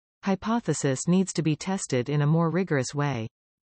Test-takers will hear a short sentence in this task.